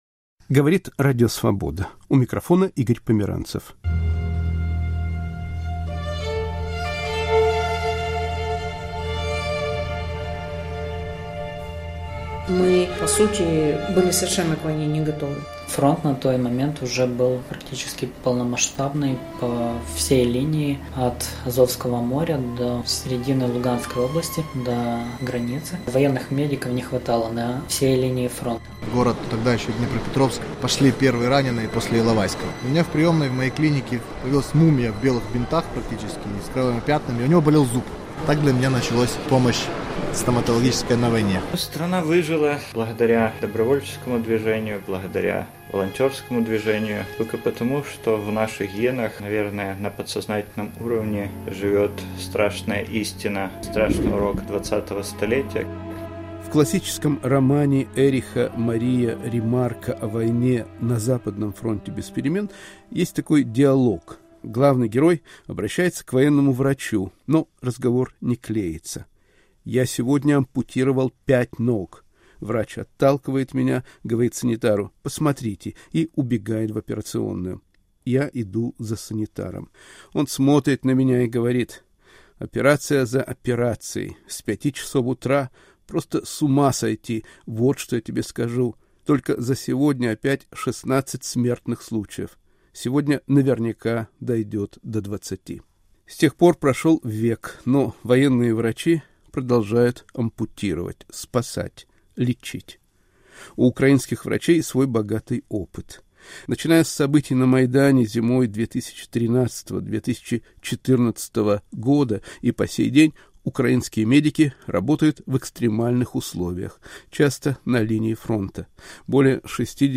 Рассказывают украинские военные врачи